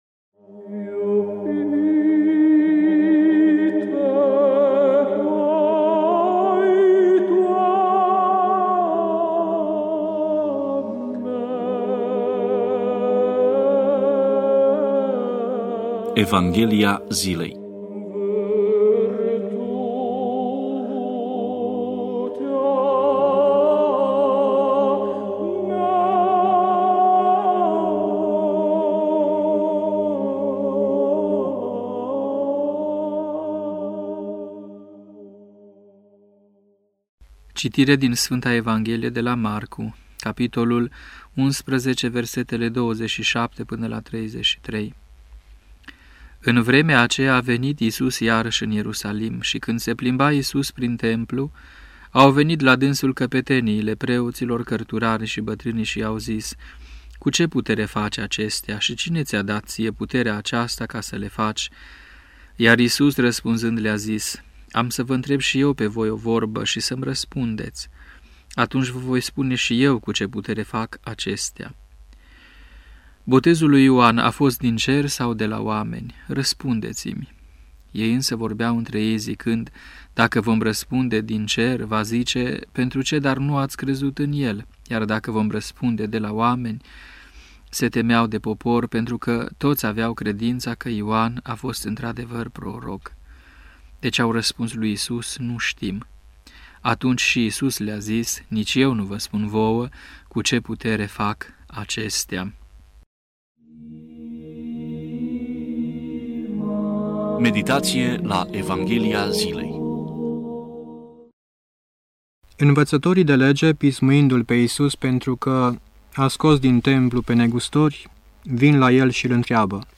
Meditație la Evanghelia zilei